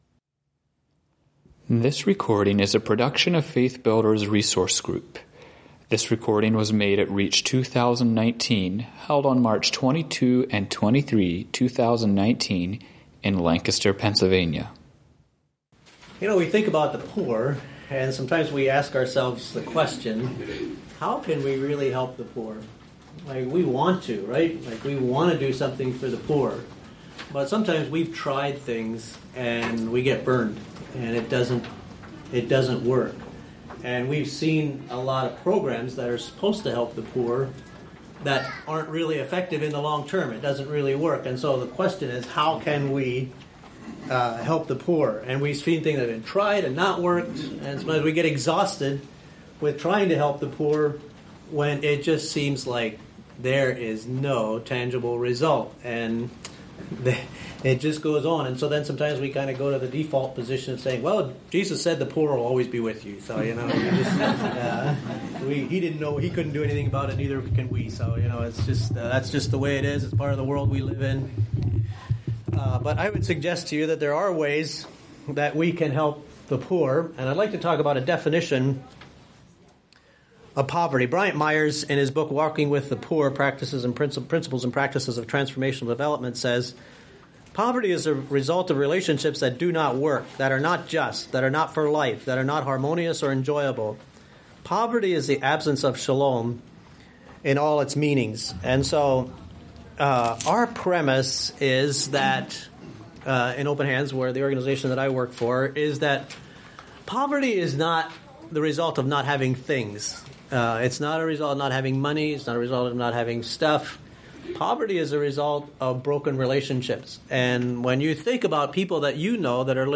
This workshop looks at fundamental causes of poverty. It demonstrates how the formation of community is the best way to alleviate poverty.